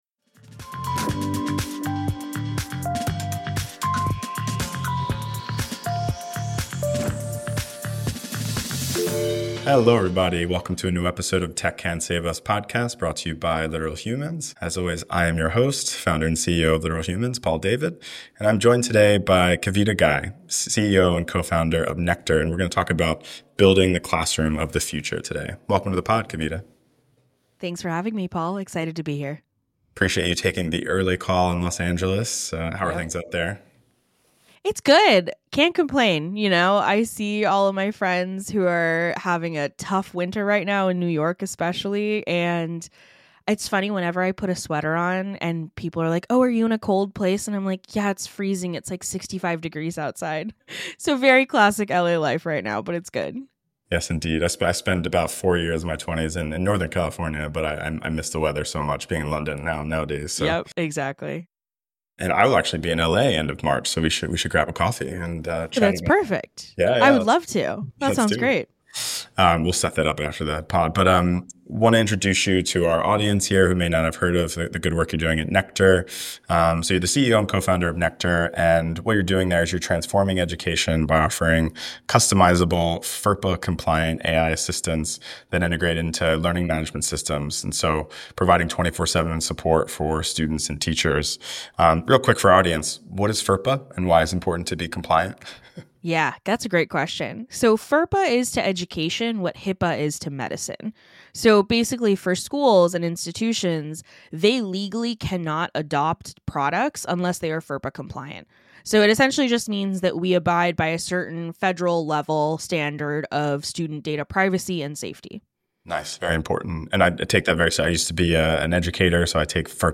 sit down with founders, CEOs, and innovators at mission-driven tech companies